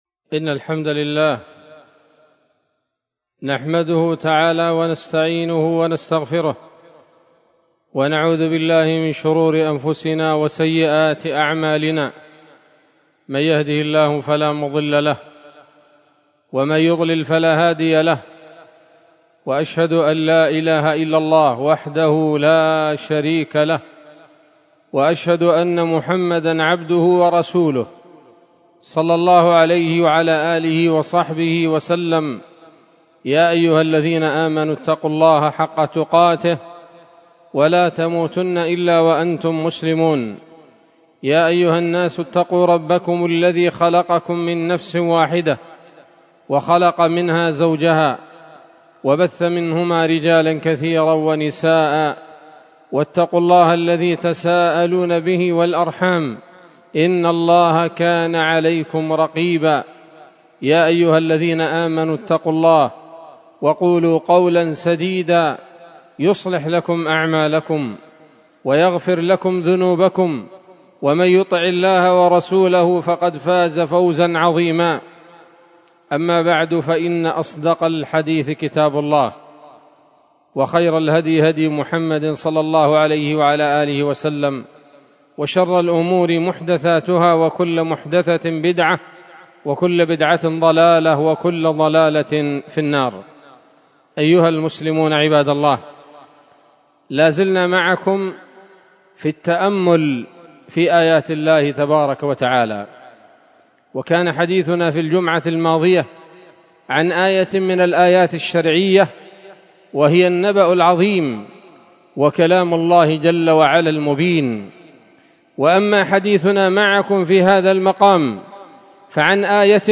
خطبة جمعة بعنوان: (( عالم الملائكة )) 27 شوال 1446 هـ، دار الحديث السلفية بصلاح الدين